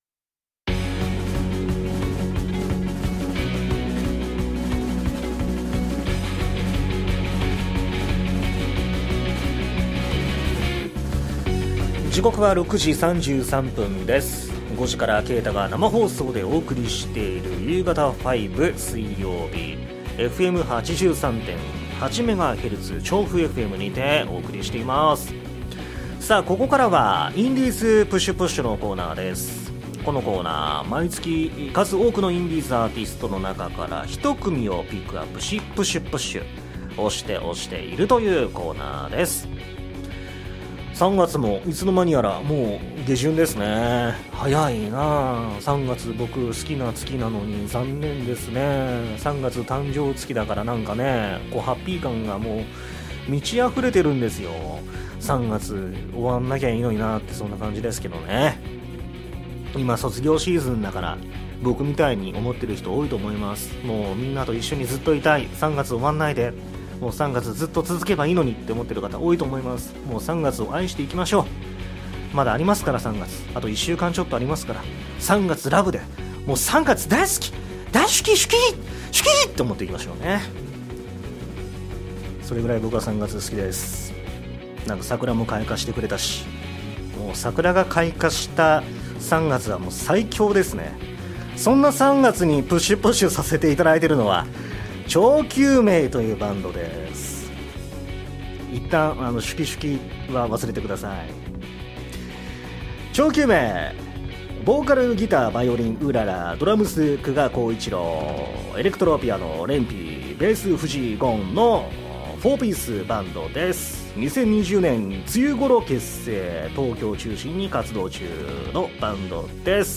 ※WEB掲載用に編集し楽曲はカットしています。